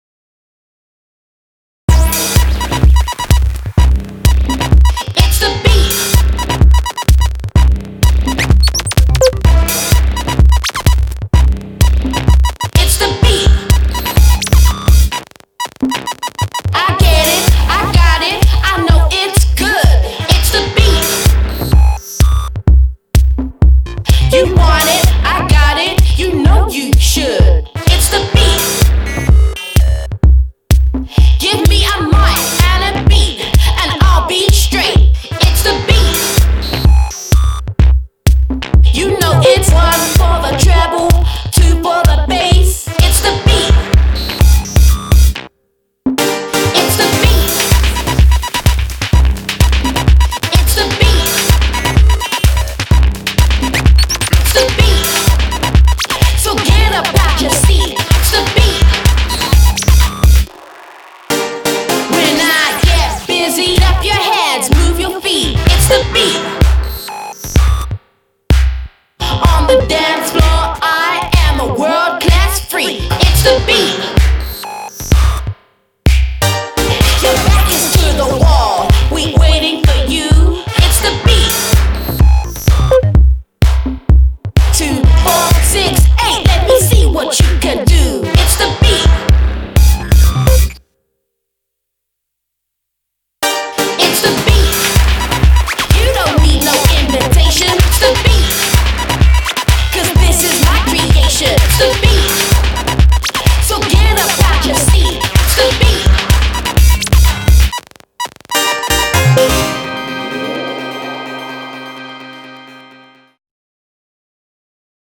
BPM127